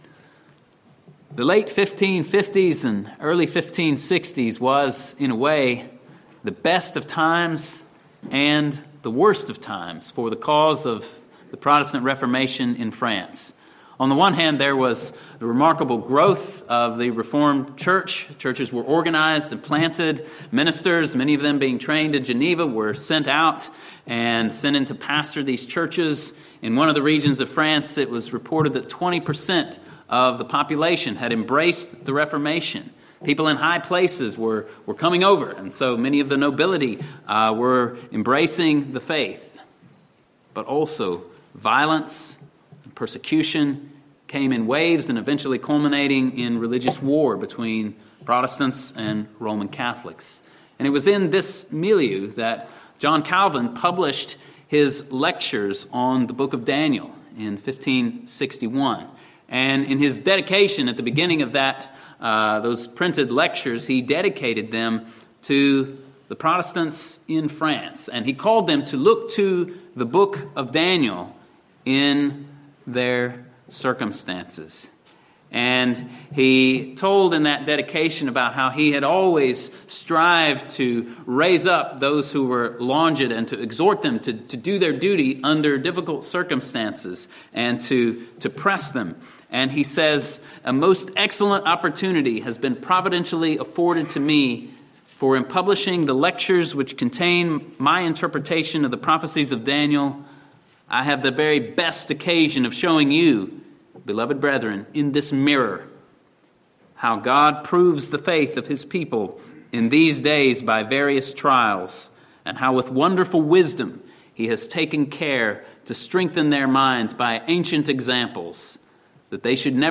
Sermons from Andover Baptist Church in Linthicum, MD.